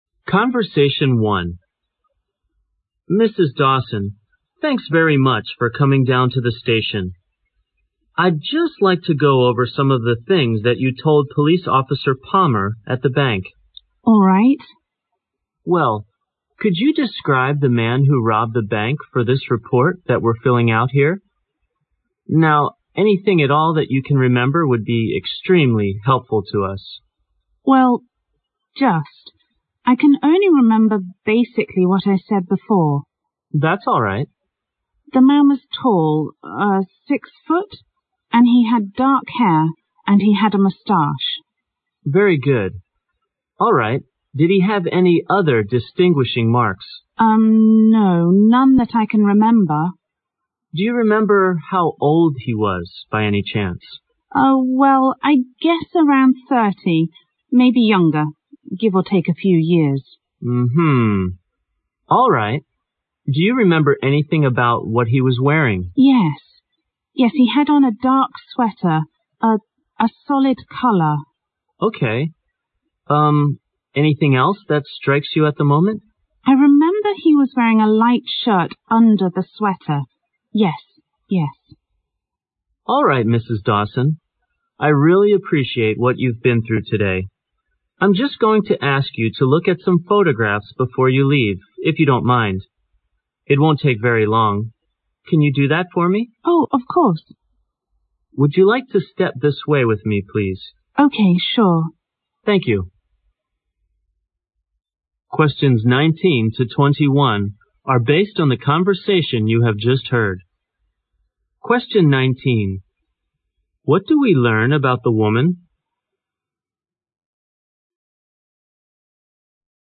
2010年12月英语四级真题听力 第3期 长对话(1)